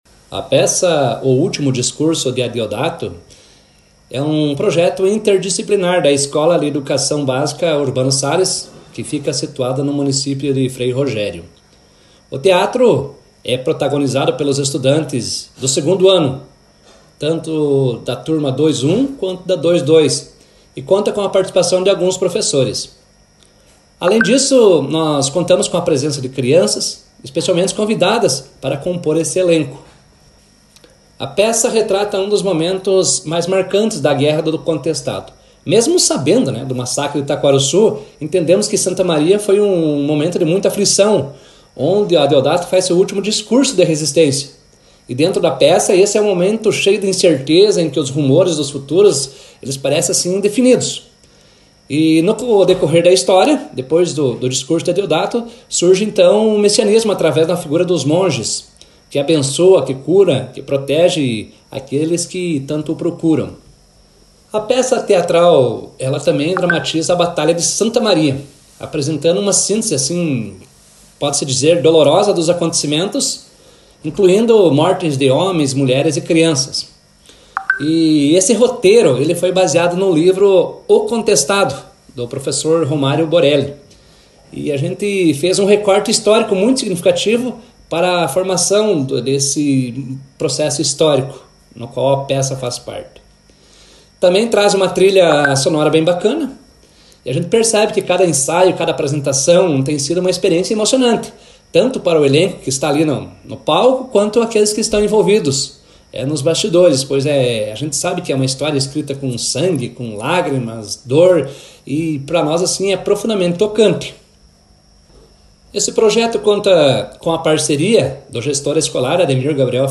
conversou com nossa reportagem e falou sobre o evento.